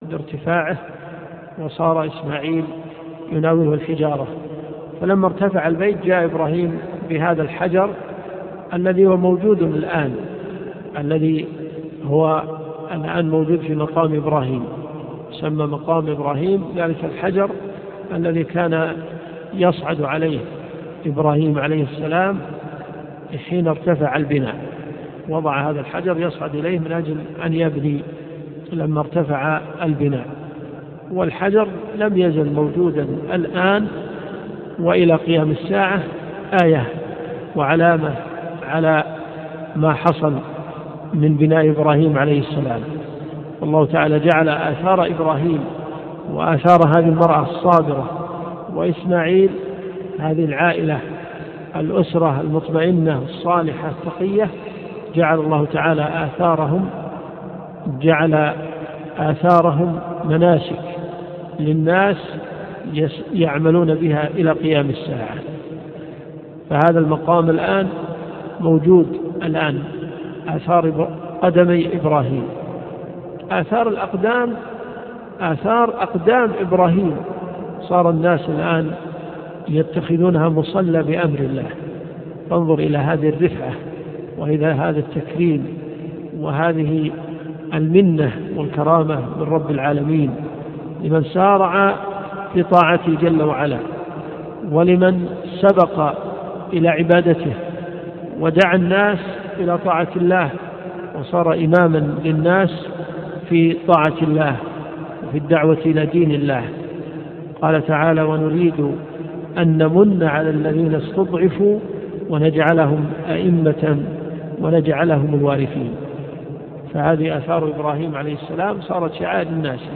محاضرة عن الحج . جامع قتيبة بن مسلم - حي الملك فهد . الرياض